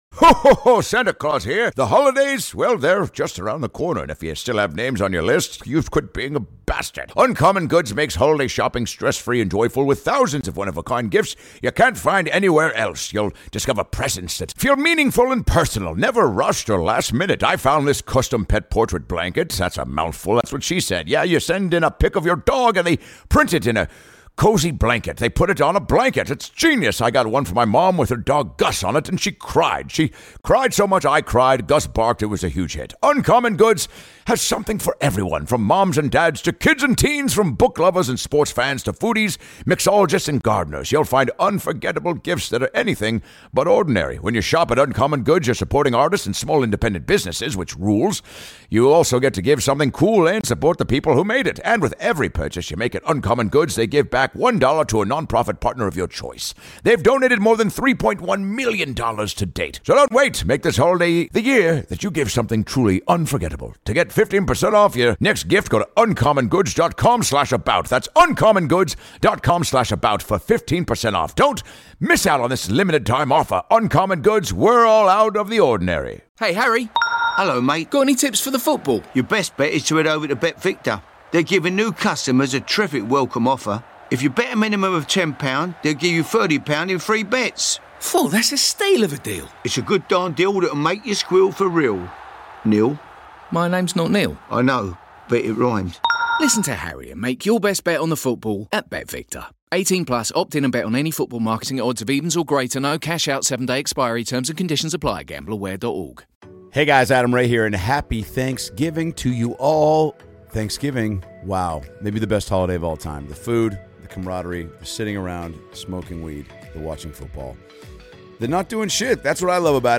#838 Best of Kansas City | Adam Ray | Stand Up Comedy
This was one of the funnest crowds, check it out!